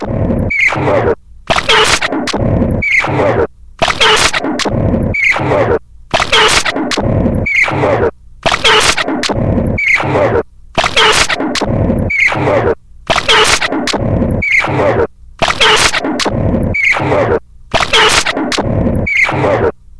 卷到卷的磁带操作/噪音循环 " 撕裂的声音
描述：口哨声跺脚声撕扯声吱吱作响记录在1/4"磁带上，并进行物理循环
标签： 磁带操纵 翻录 蹬地 带环 哨子
声道立体声